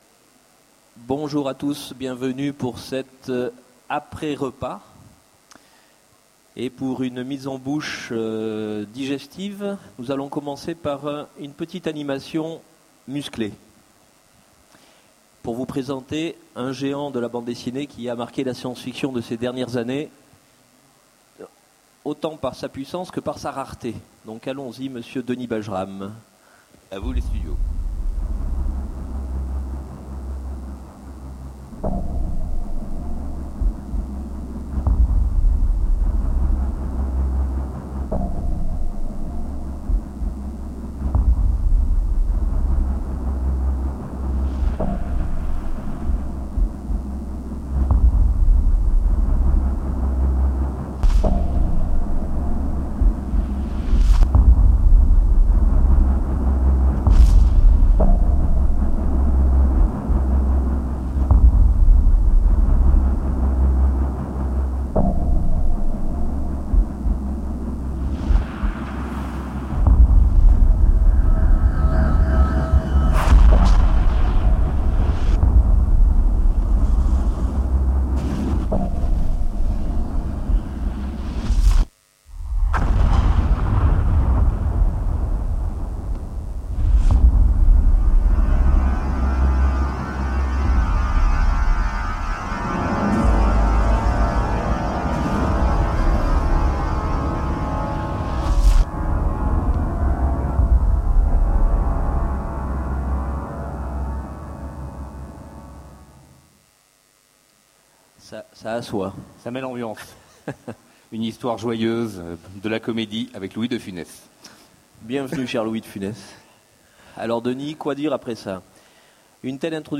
Utopiales 13 : Conférence Bajram, le combattant universel